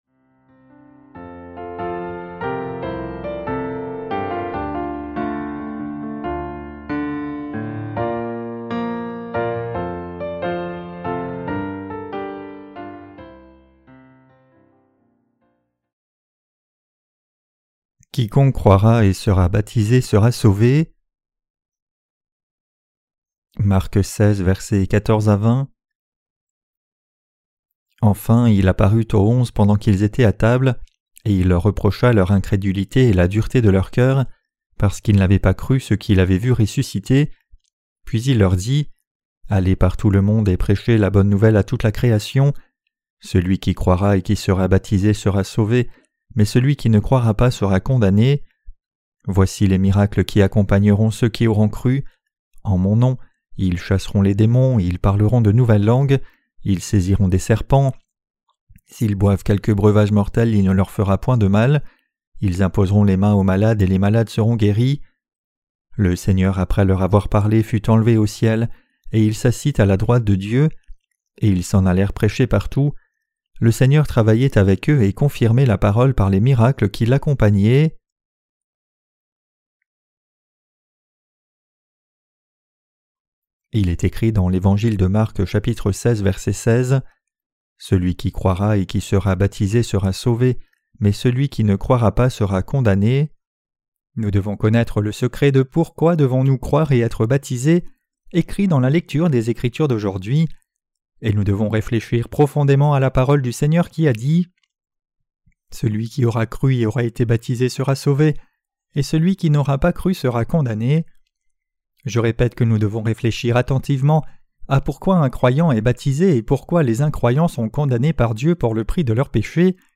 Sermons sur l’Evangile de Marc (Ⅲ) - LA BÉNÉDICTION DE LA FOI REÇUE AVEC LE CŒUR 14.